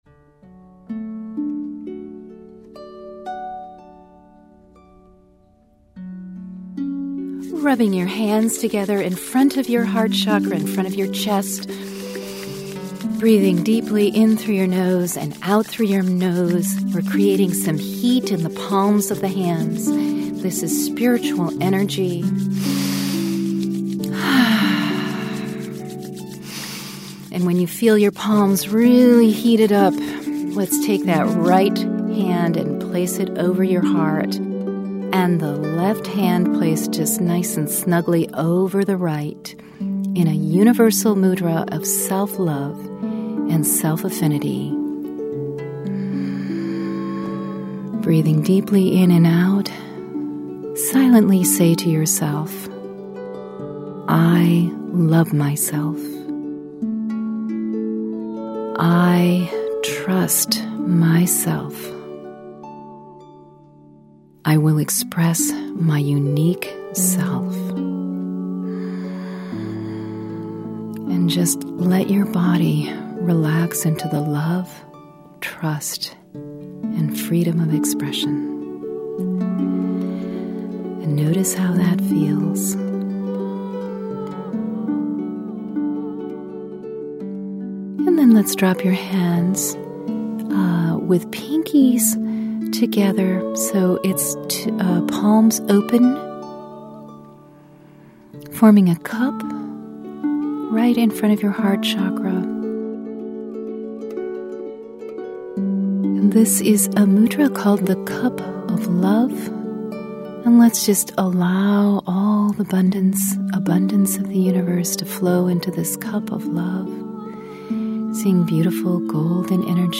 Abundance Meditation